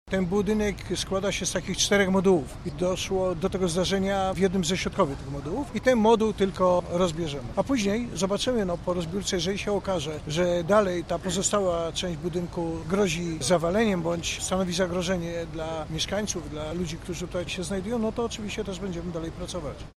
Leszek Suski – mówi Komendant Główny Państwowej Straży Pożarnej, Leszek Suski.